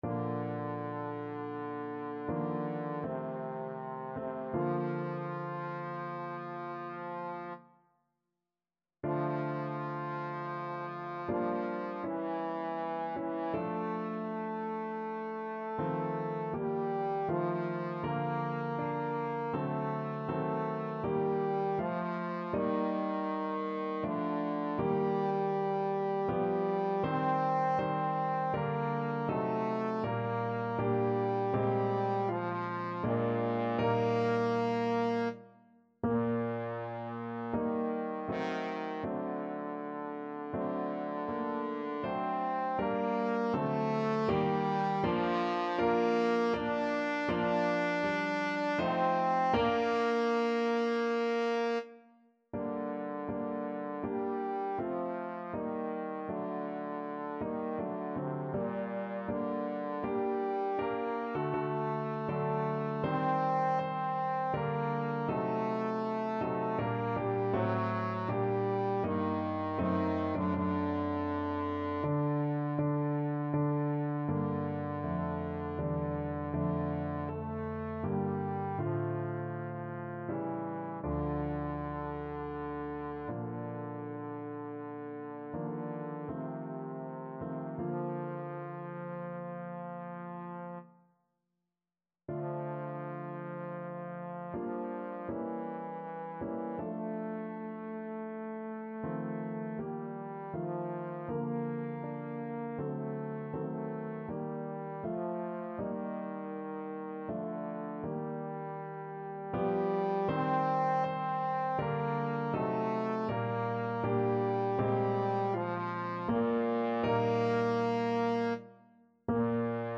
Classical Stainer, John God So Loved the World from The Crucifixion Trombone version
Bb major (Sounding Pitch) (View more Bb major Music for Trombone )
~ = 80 Andante ma non lento